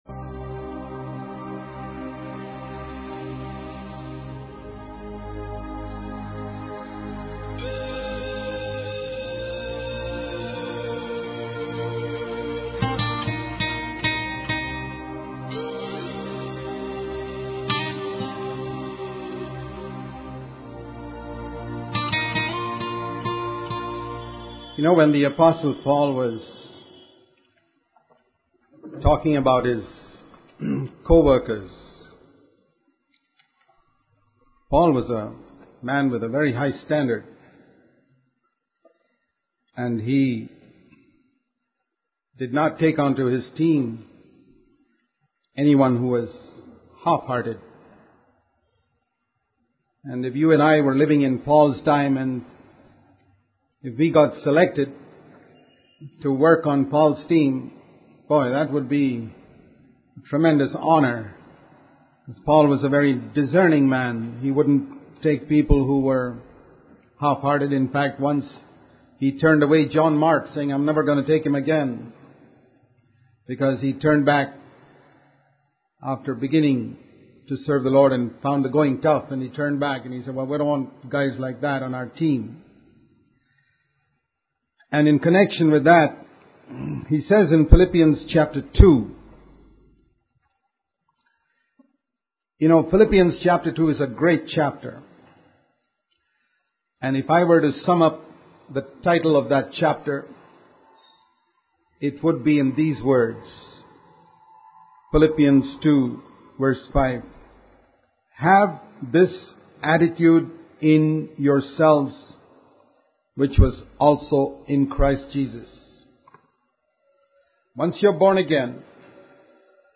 In this sermon, the speaker emphasizes the importance of using our lives and passions to please God and serve others. He highlights the example of faithful servants like Watchman Nee and encourages listeners to make the most of their time on earth.